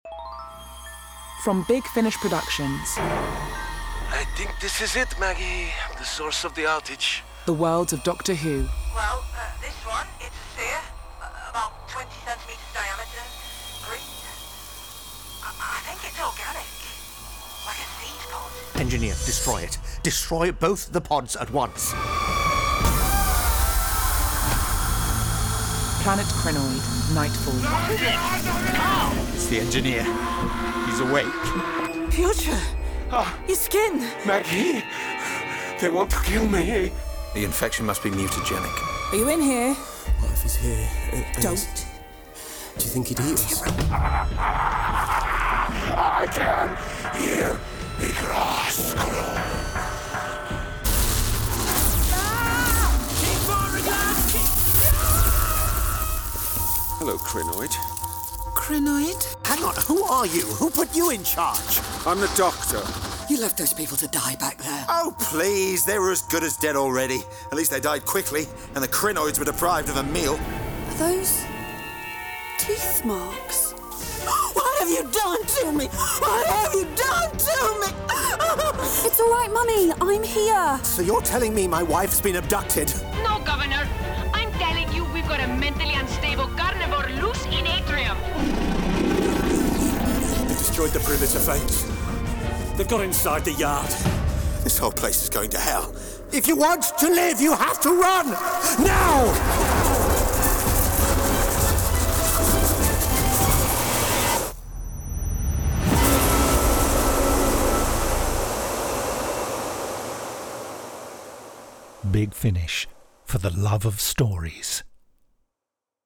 Planet Krynoid: Nightfall Released April 2025 Written by Jonathan Morris Jonathan S Powell Chris Chapman Starring Reece Shearsmith Paul McGann This release contains adult material and may not be suitable for younger listeners. From US $26.15 CD + Download US $32.69 Buy Download US $26.15 Buy Login to wishlist 18 Listeners recommend this Share Tweet Listen to the trailer Download the trailer